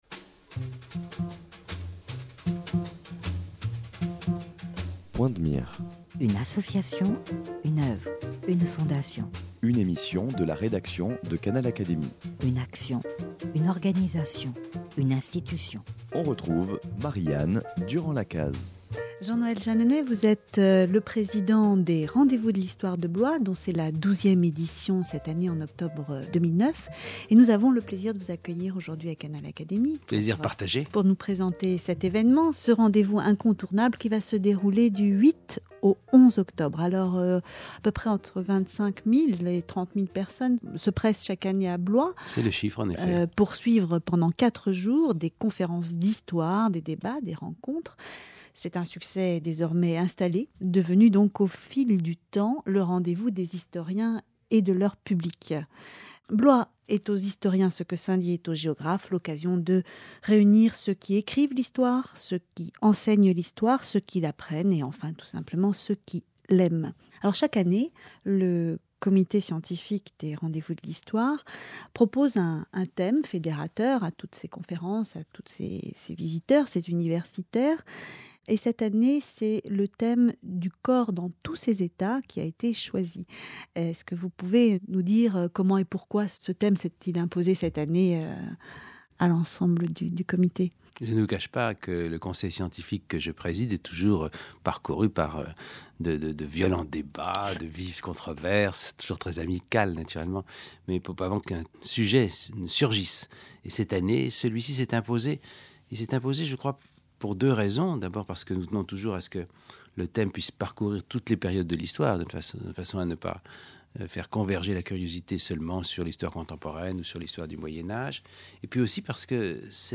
Les Rendez-vous de l’Histoire 2009 : entretien avec Jean-Noël Jeanneney